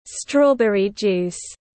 Nước ép dâu tây tiếng anh gọi là strawberry juice, phiên âm tiếng anh đọc là /ˈstrɔː.bər.i ˌdʒuːs/